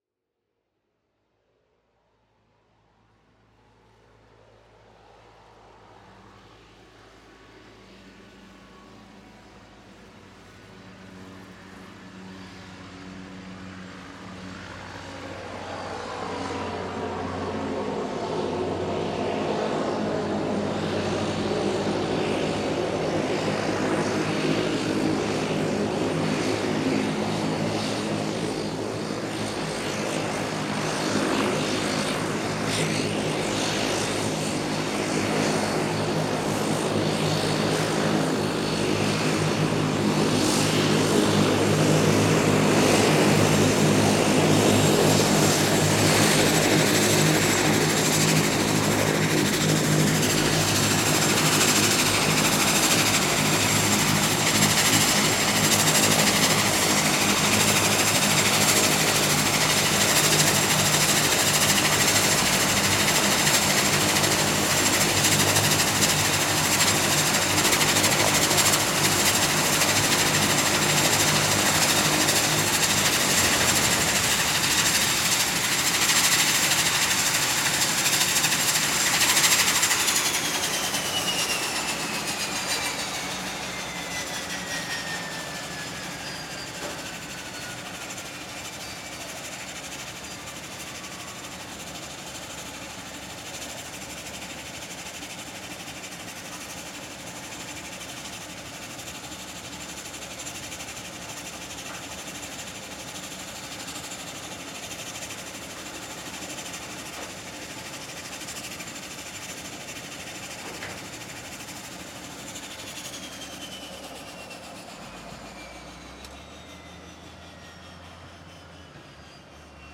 sounds / ambient / Plane.mp3
Plane.mp3